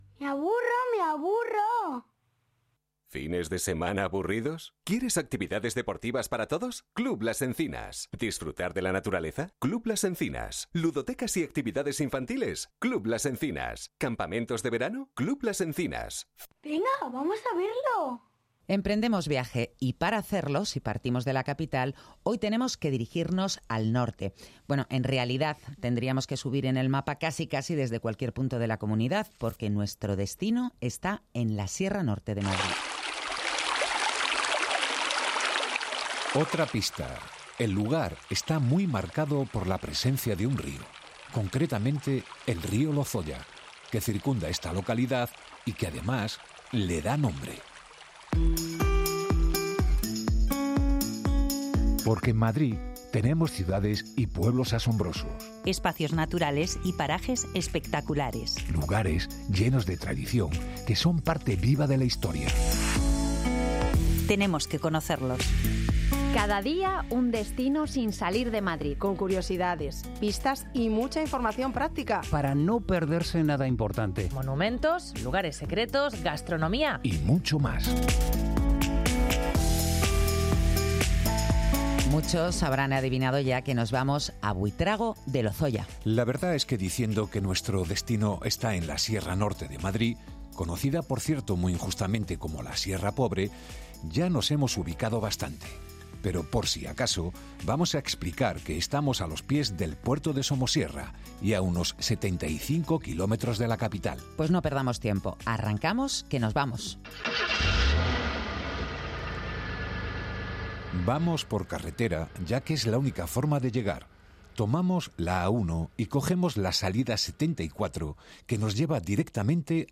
Dos periodistas